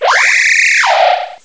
pokeemerald / sound / direct_sound_samples / cries / corvisquire.aif
The cries from Chespin to Calyrex are now inserted as compressed cries